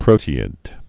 (prōtē-ĭd)